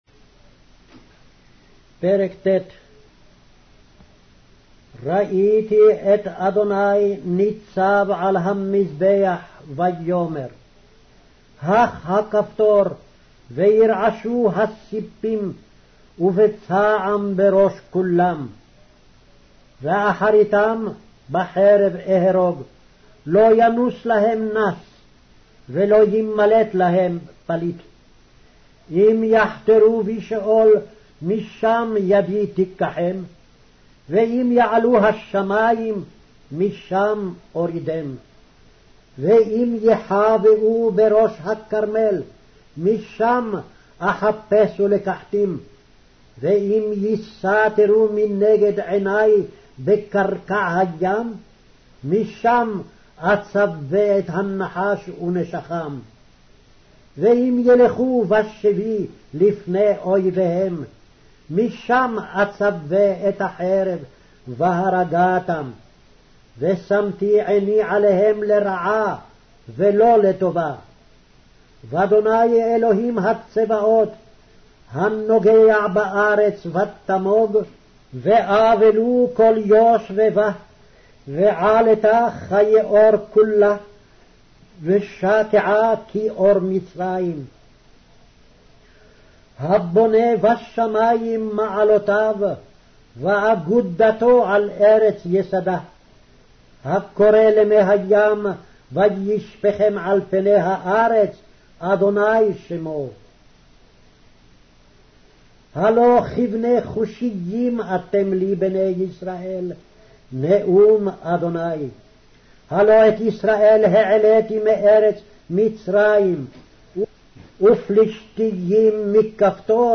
Hebrew Audio Bible - Amos 7 in Ervpa bible version